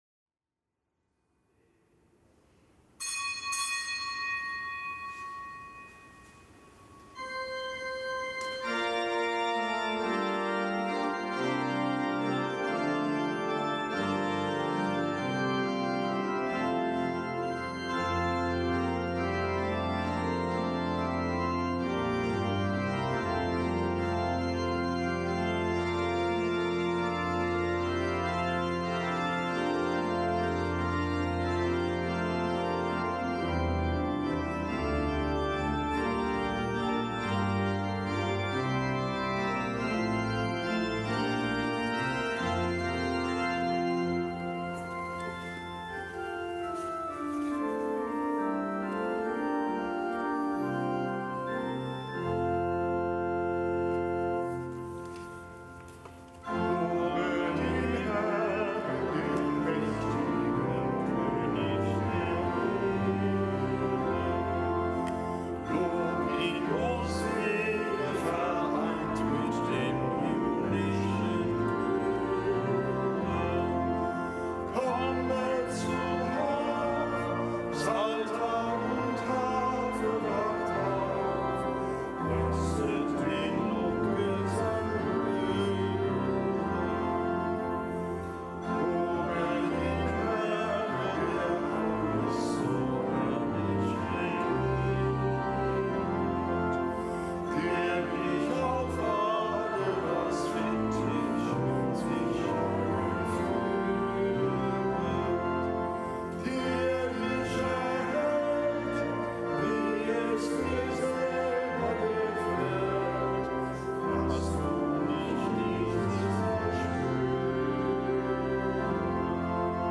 Kapitelsmesse am Montag der einundzwanzigsten Woche im Jahreskreis
Kapitelsmesse aus dem Kölner Dom am Montag der einundzwanzigsten Woche im Jahreskreis, Nichtgebotener Gedenktag des Heiligen Ludwig, König von Frankreich; und des Heiligen Josef von Calasanz, Pries